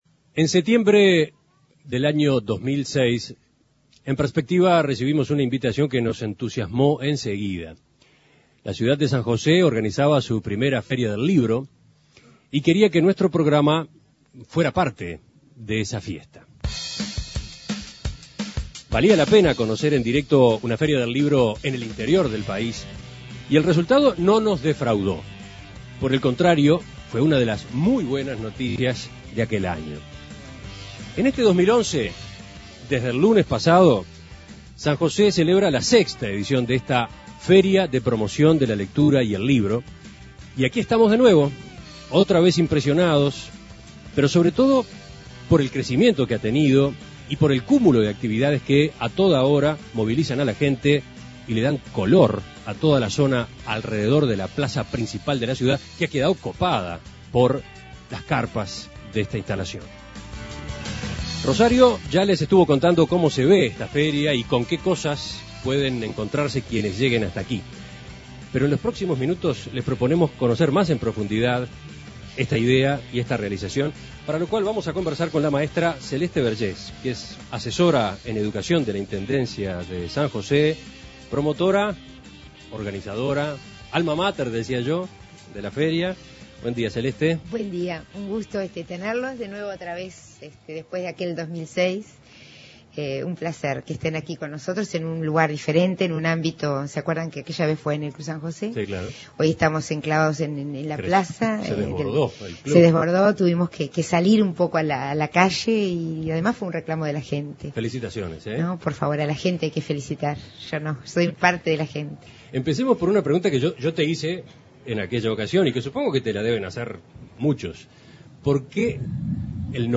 Entrevistas La feria del libro de San José sigue creciendo año a año (audio) Imprimir A- A A+ En San José, la Feria de Promoción de la Lectura y el Libro llega este año a su sexta edición.